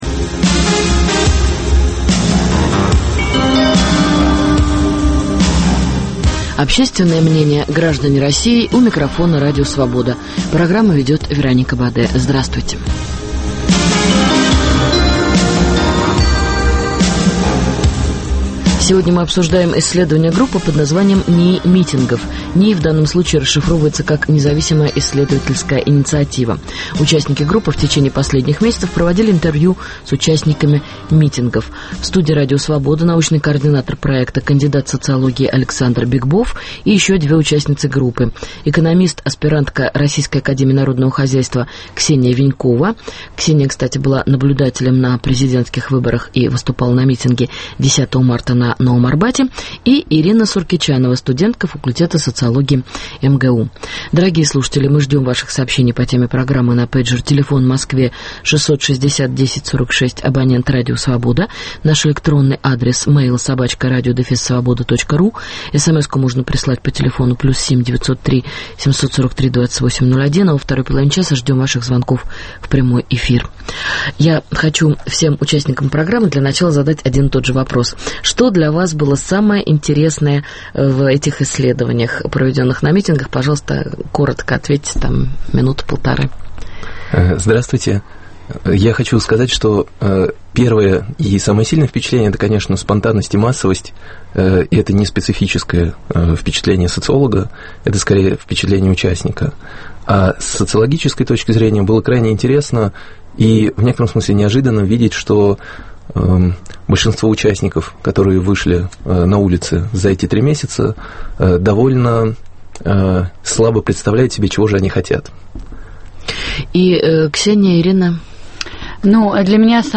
Обсуждаем социологические исследования, проведенные как на митингах оппозиции, так и на митингах в поддержку Владимира Путина. В студии Радио Свобода